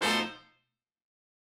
GS_HornStab-B7b2b5.wav